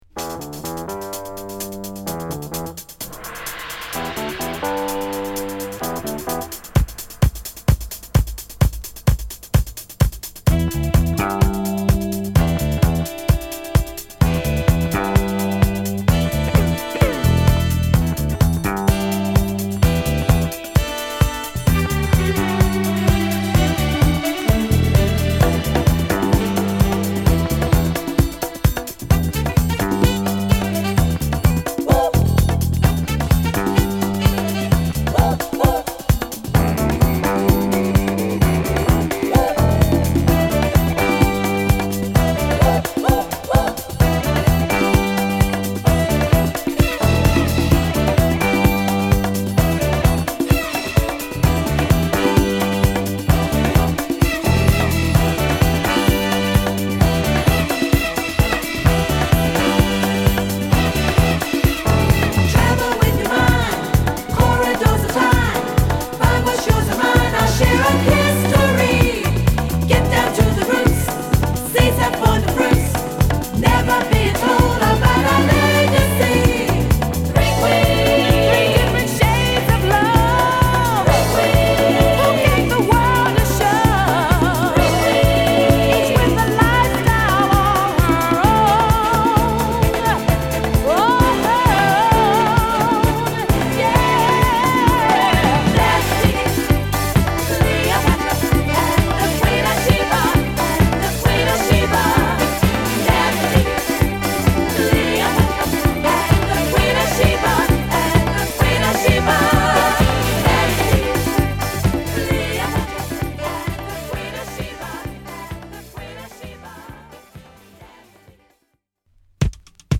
ナイスなディスコトラックを満載です！